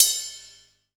Brush Ride2.wav